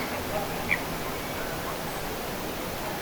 kilpikanan ääni
kilpikana_ilmeisesti.mp3